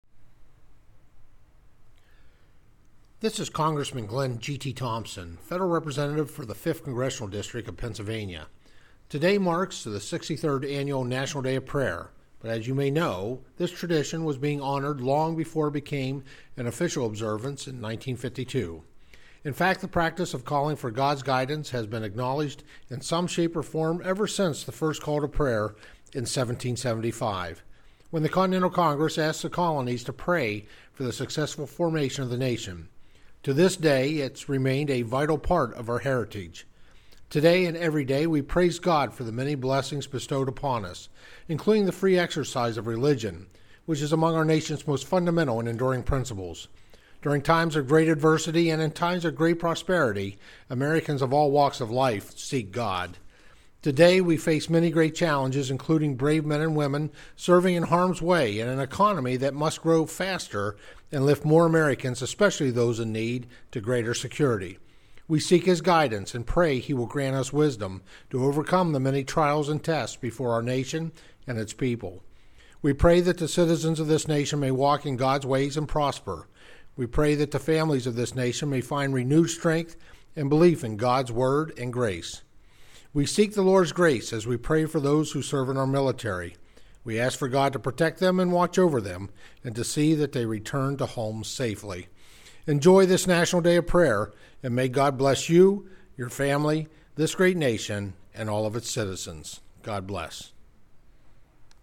Audio: U.S. Rep. Thompson Delivers Radio Address on 63rd National Day of Prayer
Washington, D.C. – U.S. Representative Glenn 'GT' Thompson, PA-5, today delivered the following radio address on the 63rd National Day of Prayer, which is celebrated on the first Thursday of May.